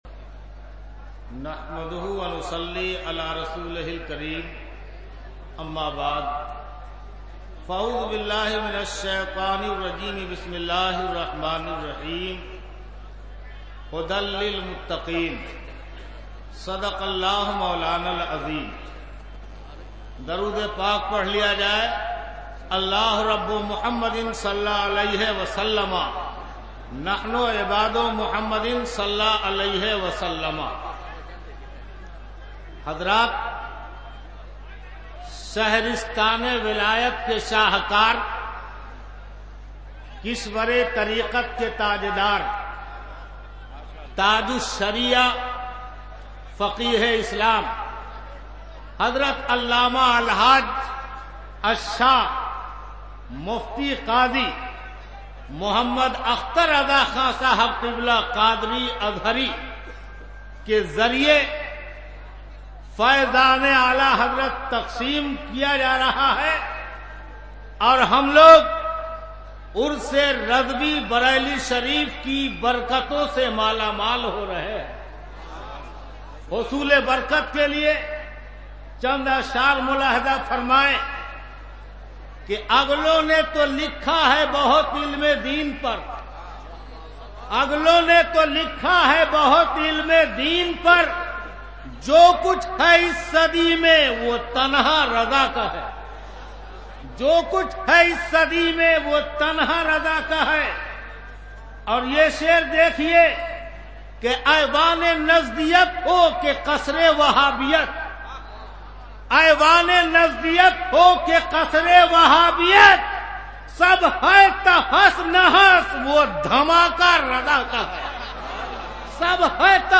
015-Speech.mp3